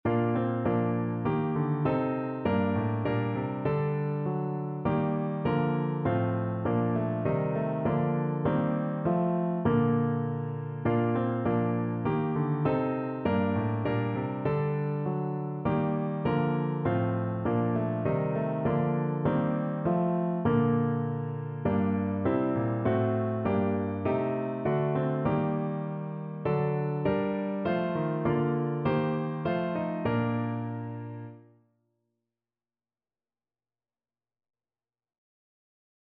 Piano version
No parts available for this pieces as it is for solo piano.
4/4 (View more 4/4 Music)
Piano  (View more Intermediate Piano Music)
Christian (View more Christian Piano Music)